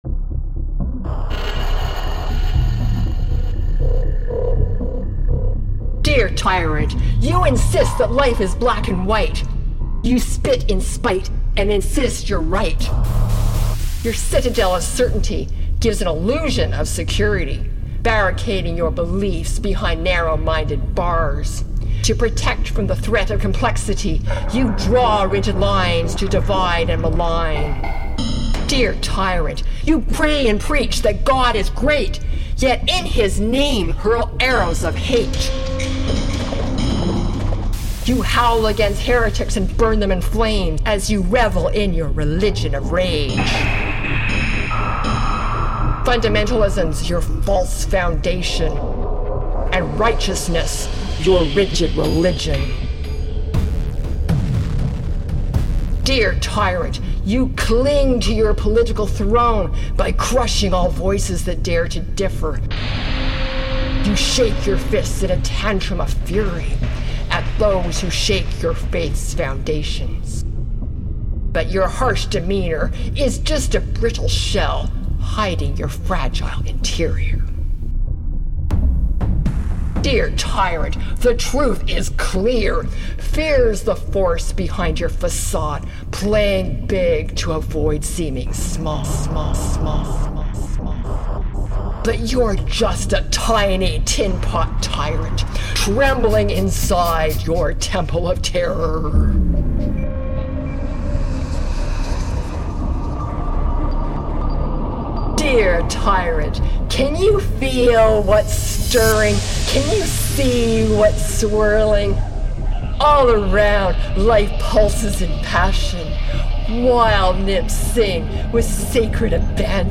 the sound of walls crumbling and voices rising.
spoken word and soundscape collaboration
Raw, immersive, and unrelenting, it’s a sonic uprising.